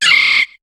Cri de Balignon dans Pokémon HOME.